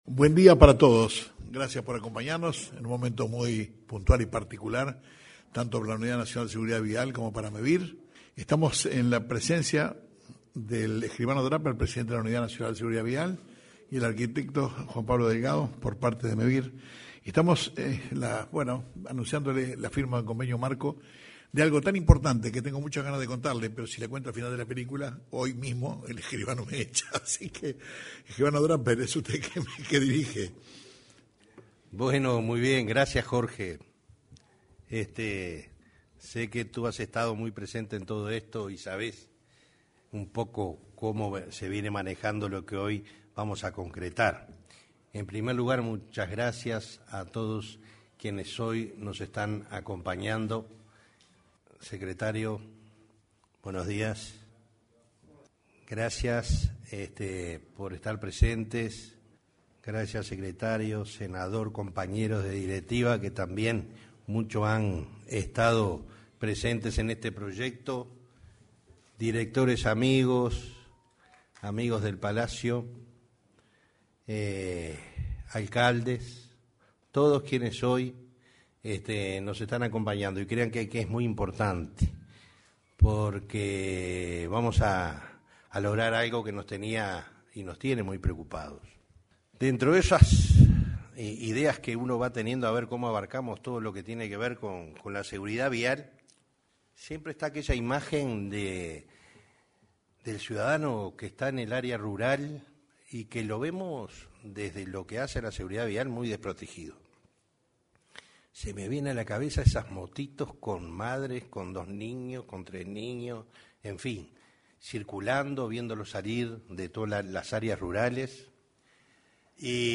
Este martes 24, en el salón de actos de la Torre Ejecutiva, se firmó de un acuerdo de cooperación para fomentar la educación vial a fin de propiciar
En la oportunidad, se expresaron el presidente de la Unidad Nacional de Seguridad Vial (Unasev), Alejandro Draper, y el de Mevir, Juan Pablo Delgado.